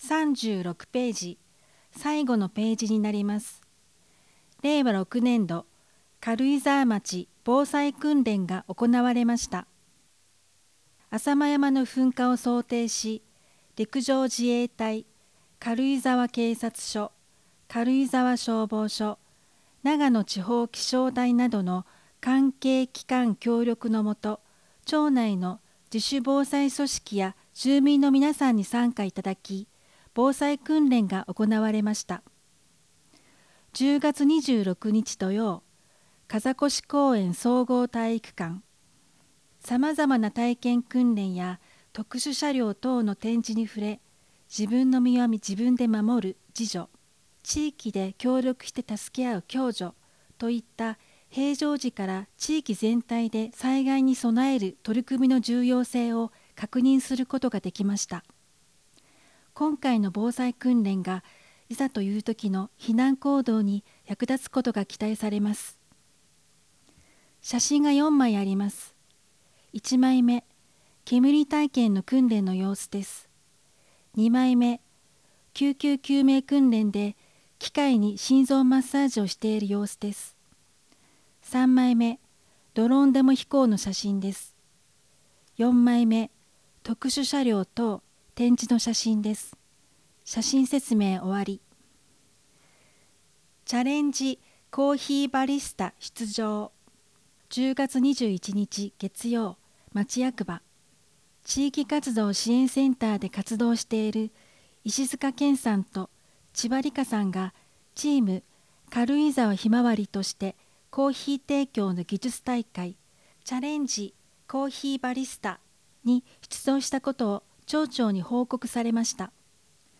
音声データ　軽井沢図書館朗読ボランティア「オオルリ」による朗読です